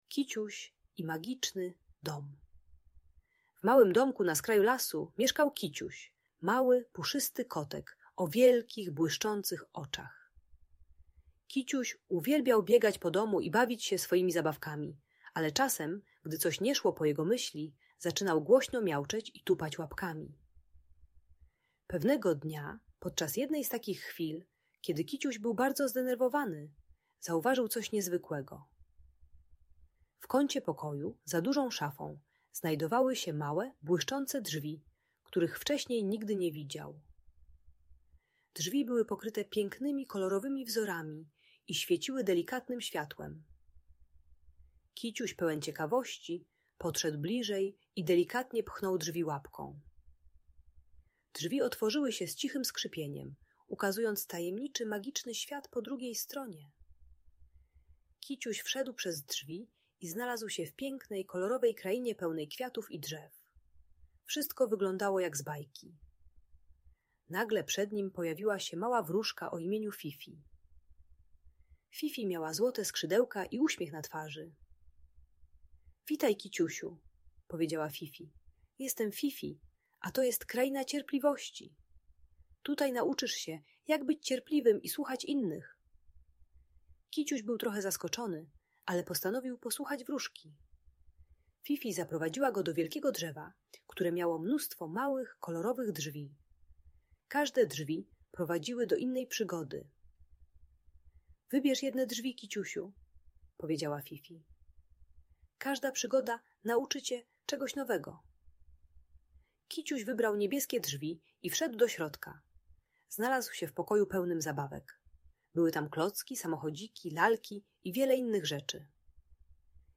Kiciuś i magiczny dom - Bunt i wybuchy złości | Audiobajka